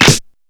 DrClap22.wav